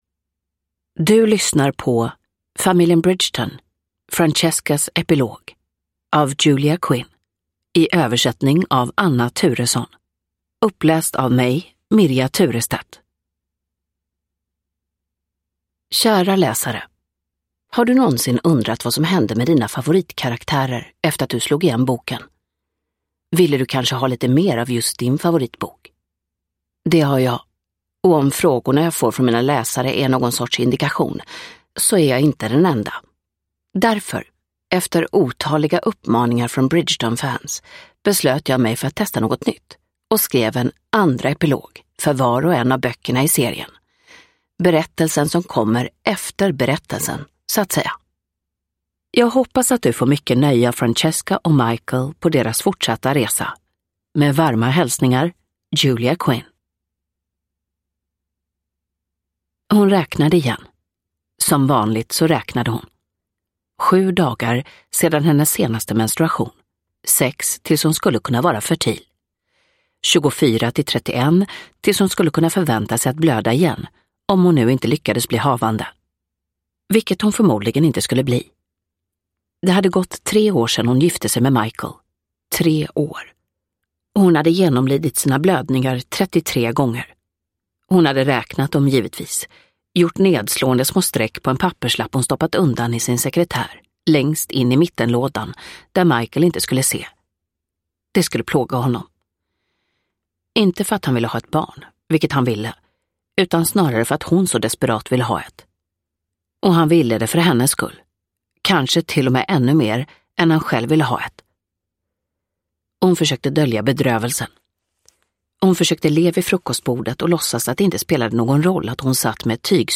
Francescas epilog – Ljudbok – Laddas ner